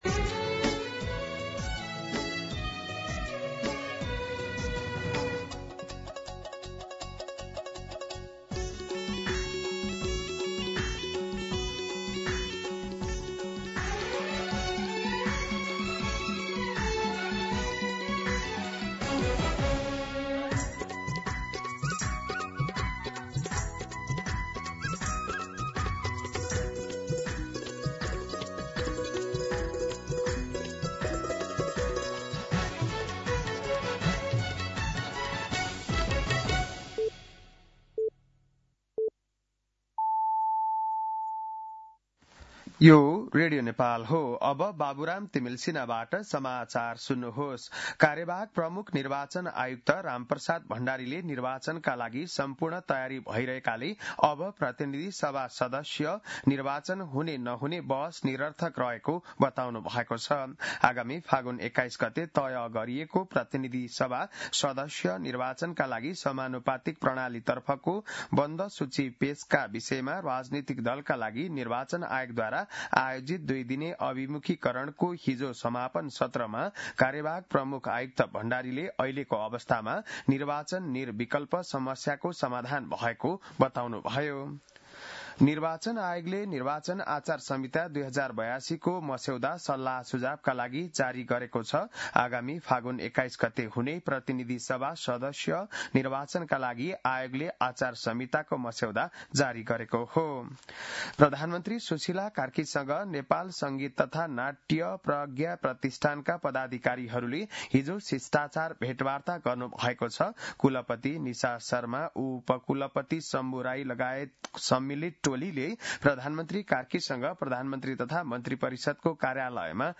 बिहान ११ बजेको नेपाली समाचार : १० पुष , २०८२